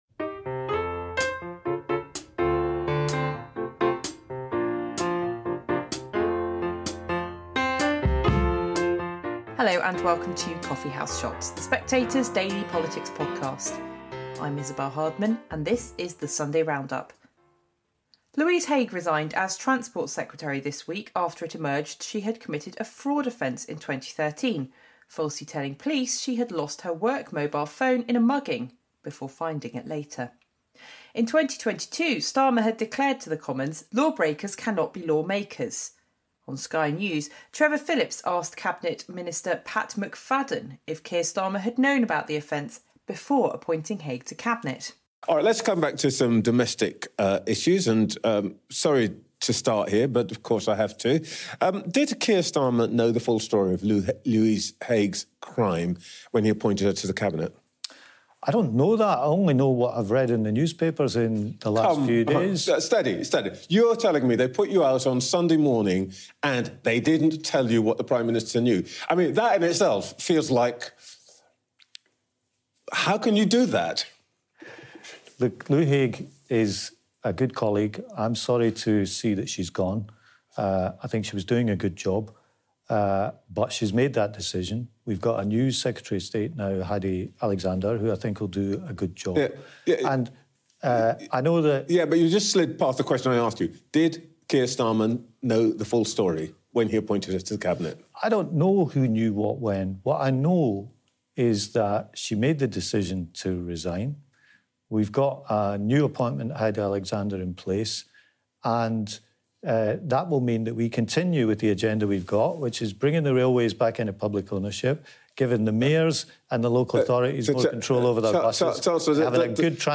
Isabel Hardman presents highlights from Sunday morning’s political shows.